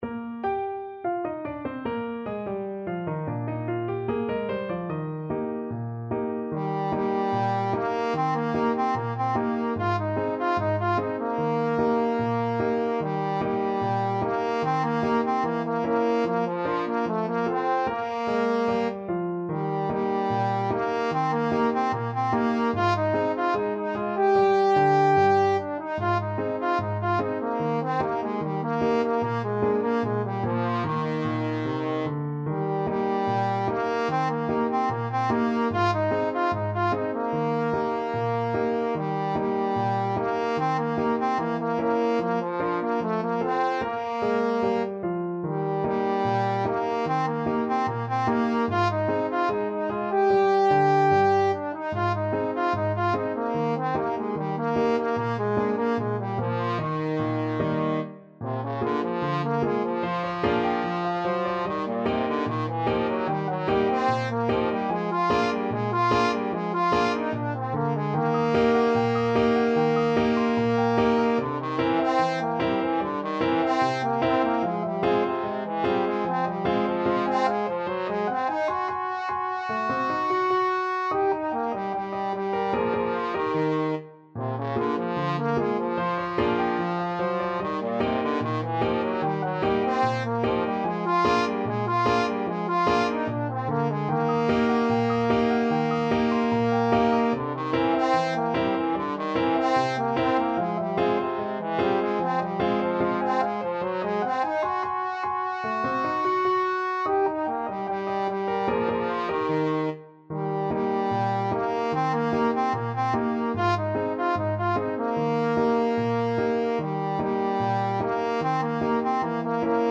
Trombone
Eb major (Sounding Pitch) (View more Eb major Music for Trombone )
2/4 (View more 2/4 Music)
Not Fast = 74
Jazz (View more Jazz Trombone Music)